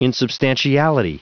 Prononciation du mot insubstantiality en anglais (fichier audio)
Prononciation du mot : insubstantiality
insubstantiality.wav